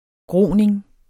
Udtale [ ˈgʁoˀneŋ ]